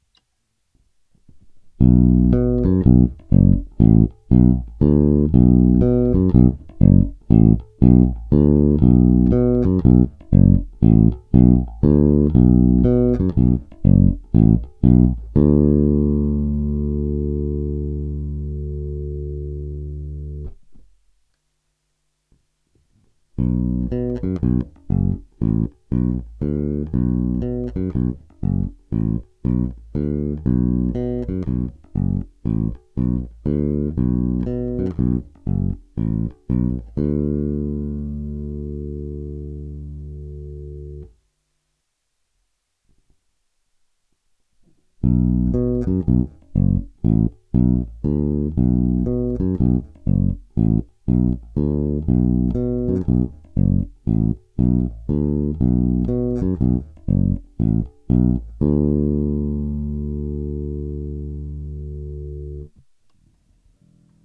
Nahráváno přímo do zvukovky bez úprav, jen normalizováno.
Druhá nahrávka je identická, ale normalizovaná dohromady, aby byl patrný rozdíl hlasitostí při přepnutí. Pořadí je čtyři kola cívky sériově - čtyři kola pouze cívka blíže ke kobylce - čtyři kola cívky paralelně. Basa je lip(b)ová kopie Musicmana Apollo, pasivní, se snímačem Bartolini, celkem nové struny Dean Markley, roundwoundy, niklové.
Rozdíl hlasitostí